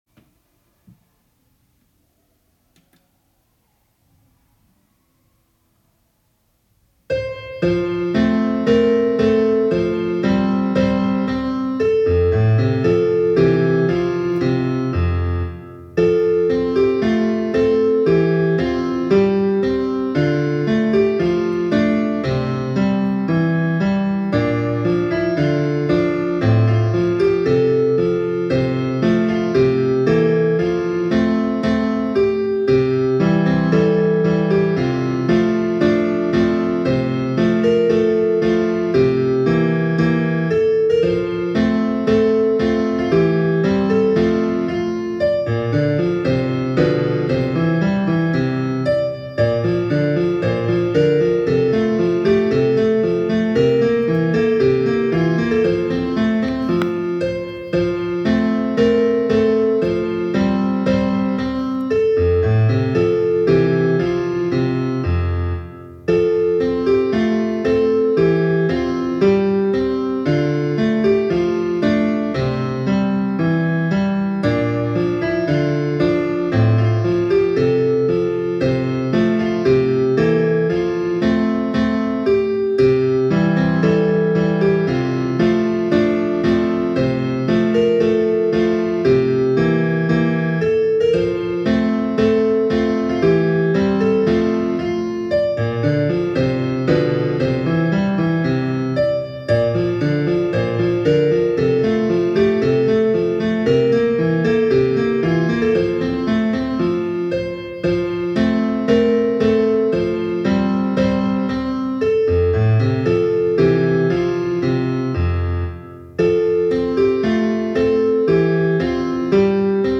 校歌演奏.m4a